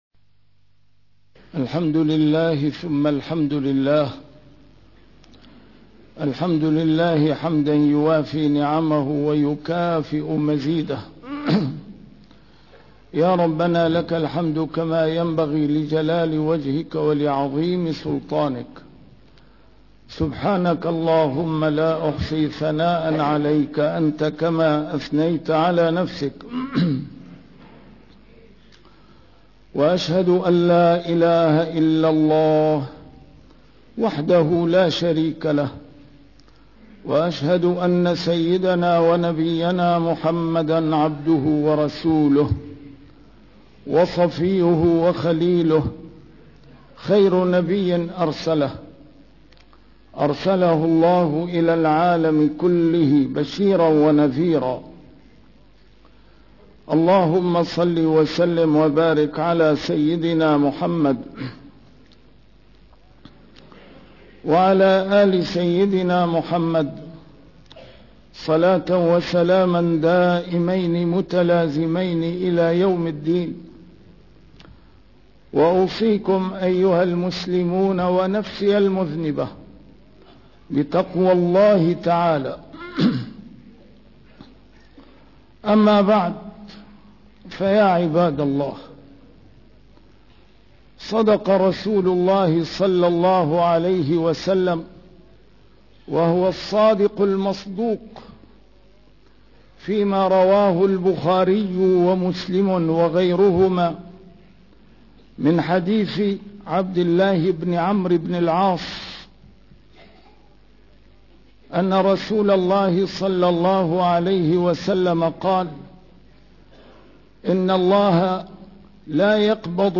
A MARTYR SCHOLAR: IMAM MUHAMMAD SAEED RAMADAN AL-BOUTI - الخطب - قناع جديد لمحاربة الإسلام